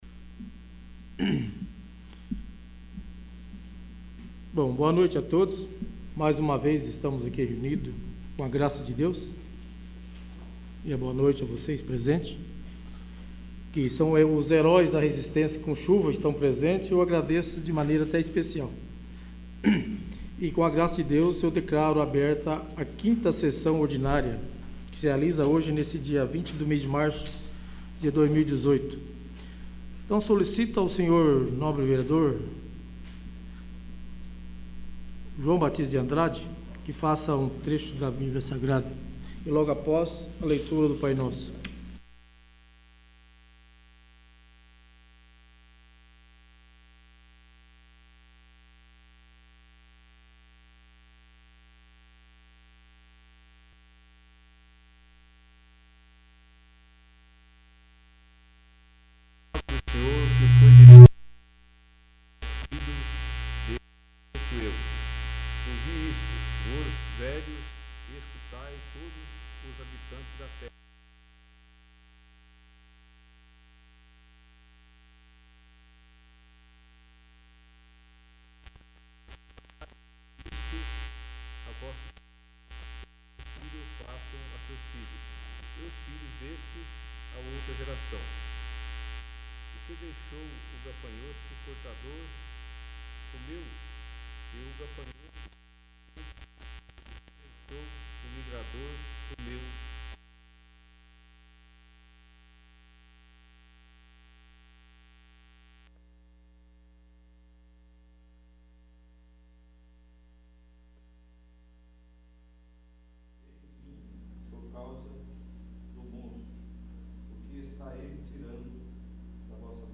5º. Sessão Ordinária